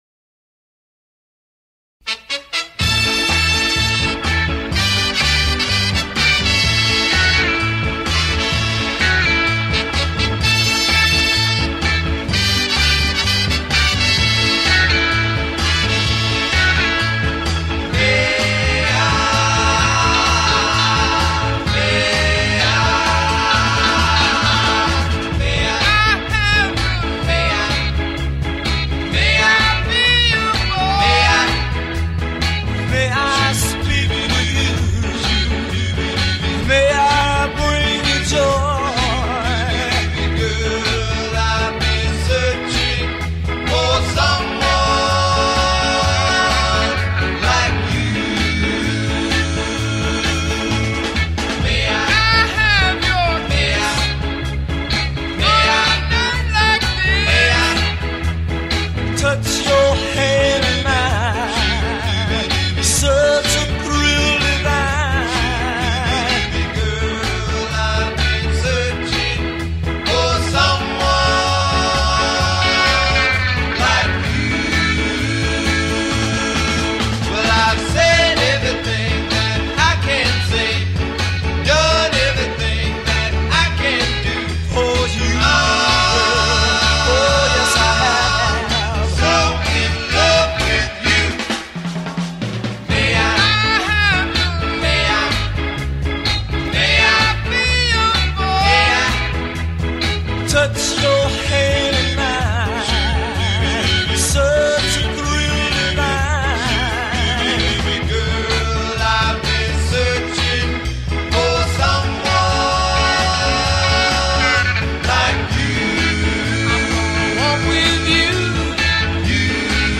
Blue-eyed soul band from Portsmouth